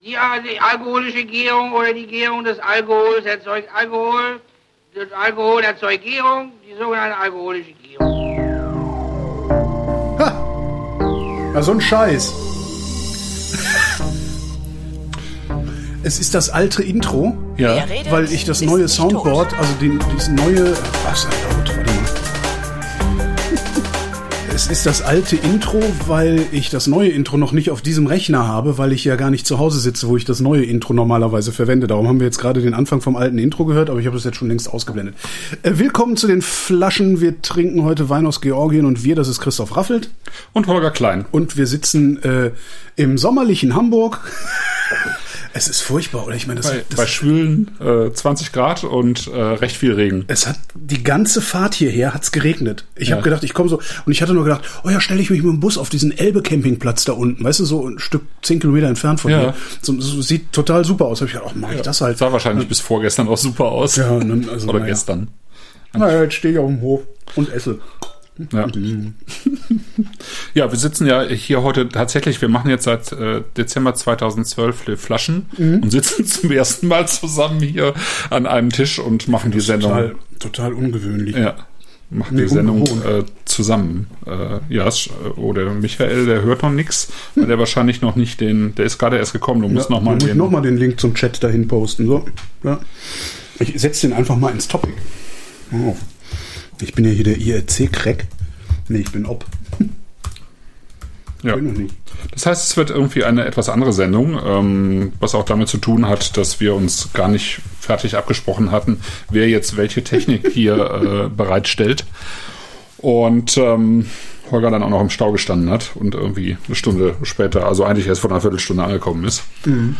Aber sonst bleibt alles gleich: Wir trinken (diesmal 2019er Tetri Kamuri-Tsolikouri von Iberieli, 2019er Chinuri von Samtavisi Marani und 2019er Otskhanuri Sapere Rosé von Gvantsa’s Wine) und plaudern über dies und das.